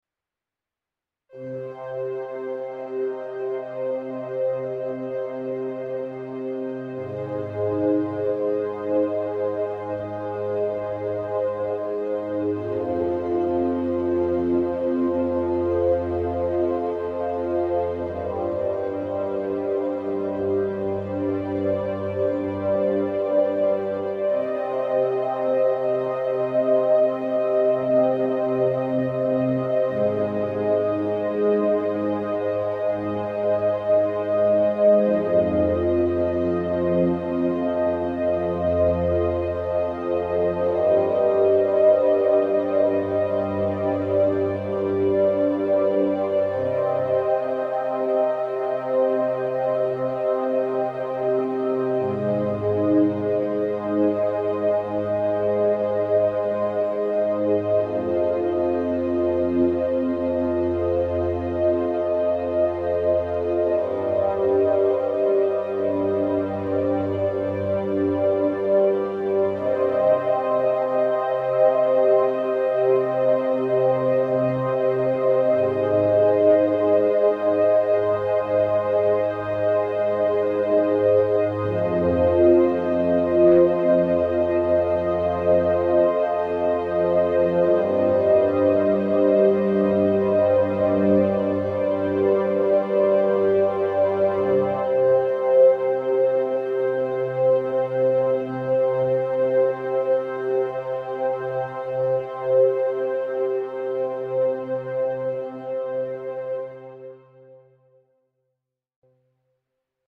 an atmospheric piece with an evolving pad sound